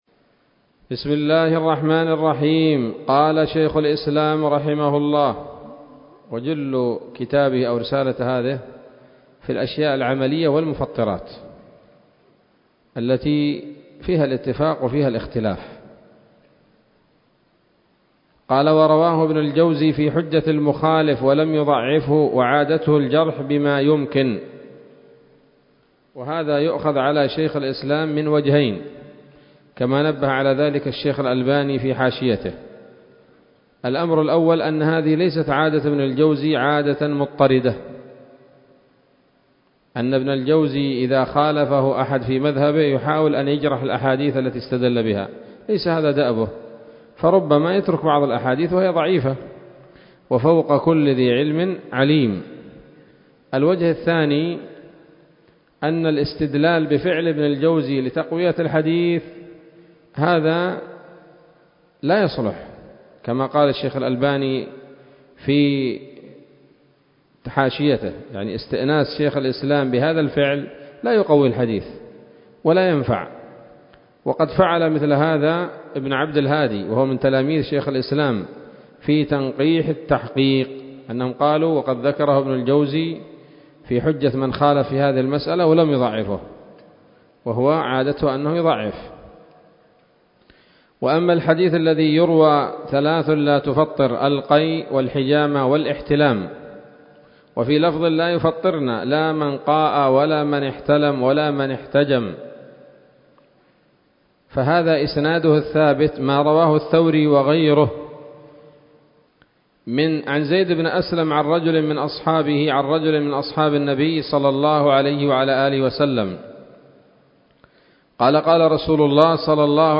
الدرس الثالث من التعليق على رسالة حقيقة الصيام لشيخ الإسلام رحمه الله